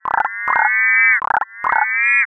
Synthesize this... Froggy
I used phaseplant and a spectrogram to replicate the sound
I replicated it to the best of my ability as there are a lot of things going on in the sound i dont fully understand yet